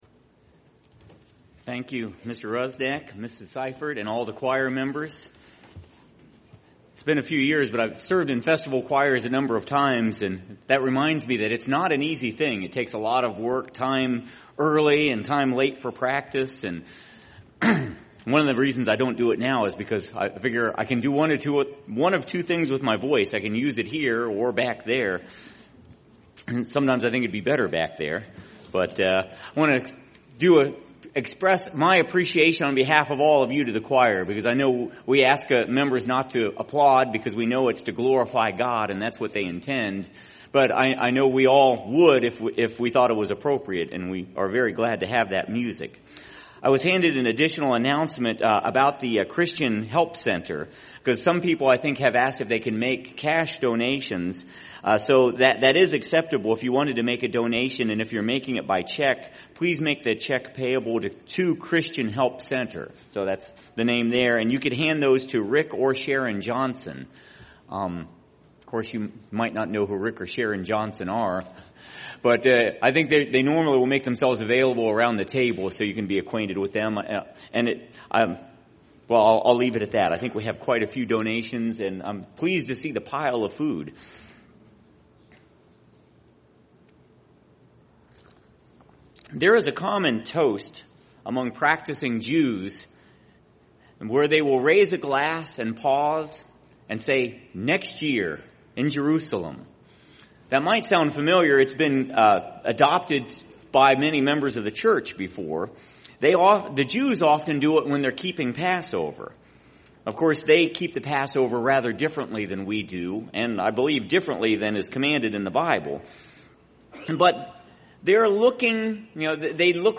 This sermon was given at the Cincinnati, Ohio 2015 Feast site.